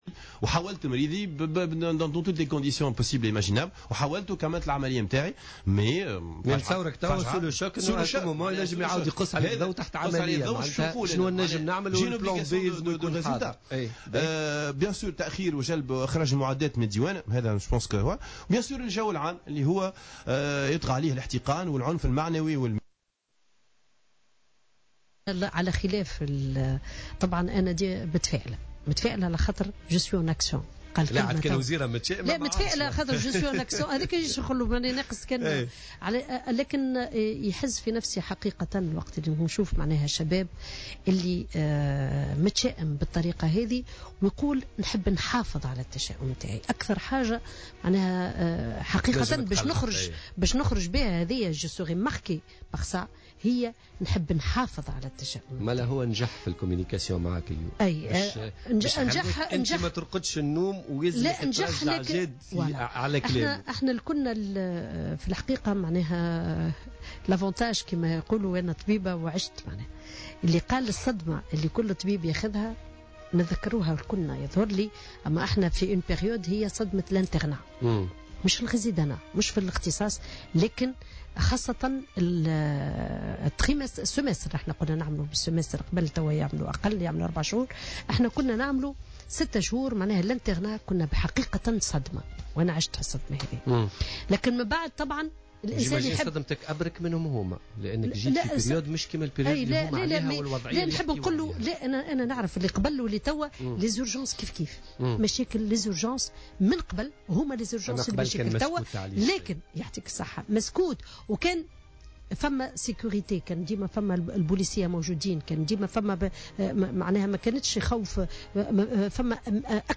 قالت وزيرة الصحة سميرة مرعي فريعة خلال حضورها في برنامج "بوليتكا" اليوم إن الوزارة ستقوم بتكوين 120 طبيبا السنة الجارية في 9 اختصاصات من بينها طب النساء والتبنيج والجراحة العامة وطب النفس للعمل في الجهات الداخلية.